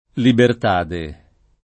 libertade [ libert # de ]